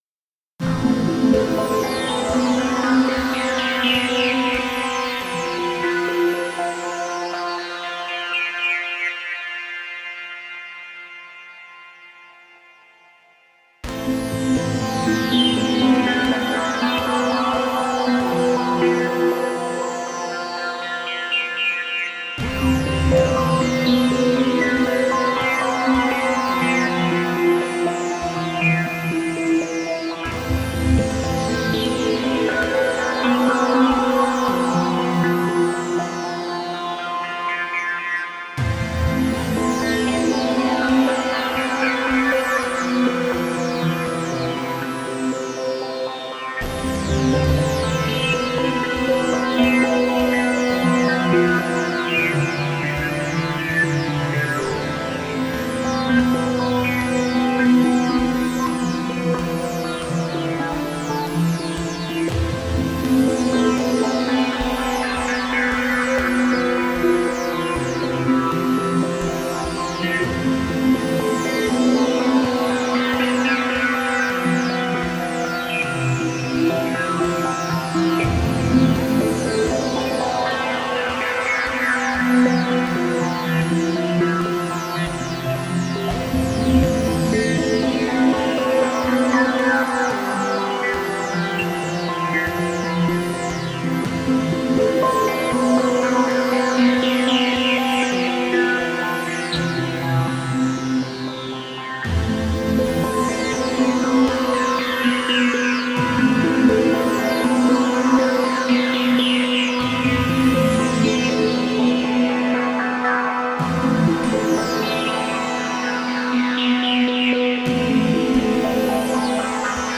synth-based ambient with pulsating lines